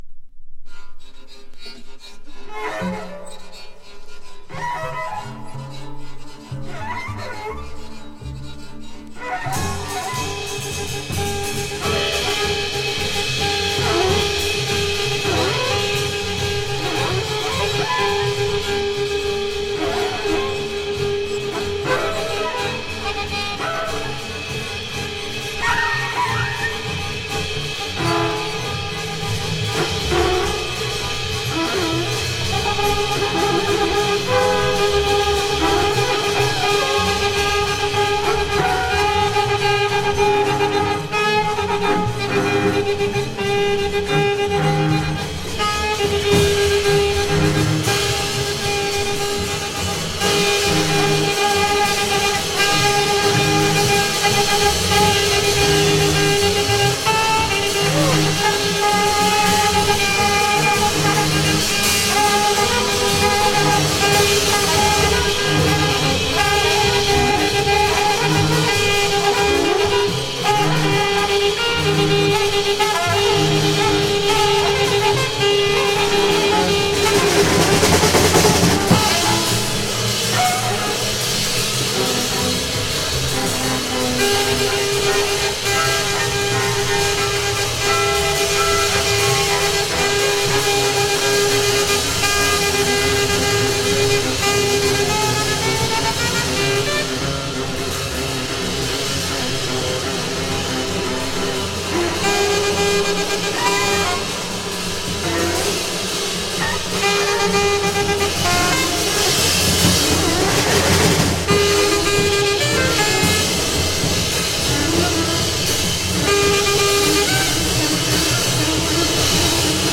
フランスの前衛ジャズ・シーンを牽引したミュージシャンのひとり
全編重厚で創意に満ちた素晴らしい演奏です。